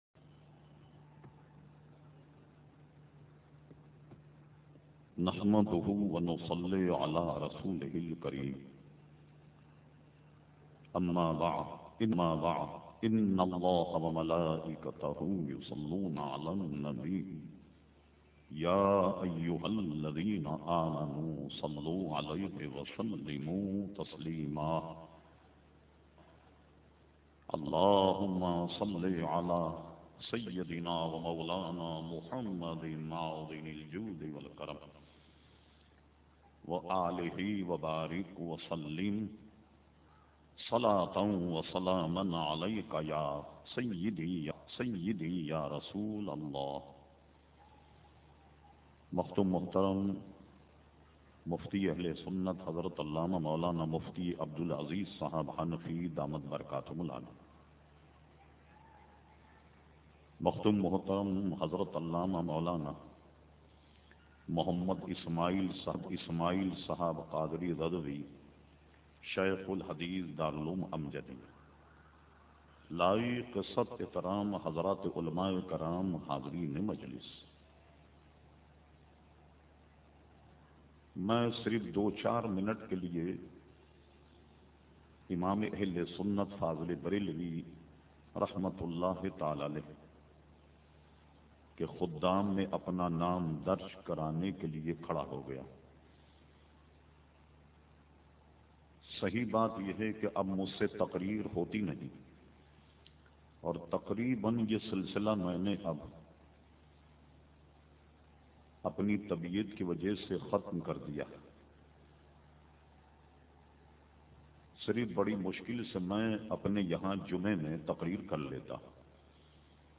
speech2.mp3